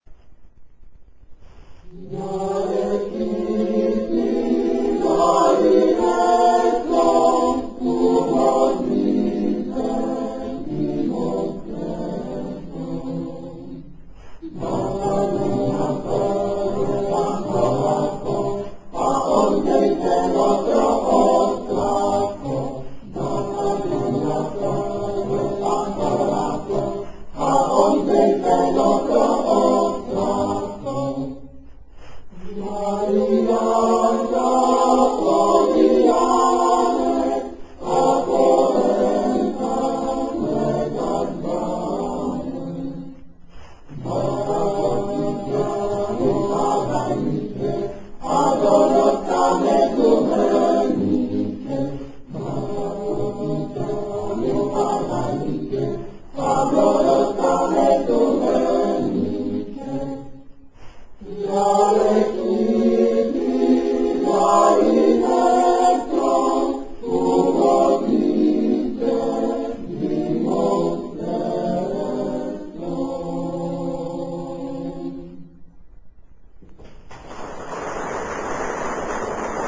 18. komorní koncert na radnici v Modřicích
Vybrané třebíčské koledy (texty) - amatérské nahrávky ukázek: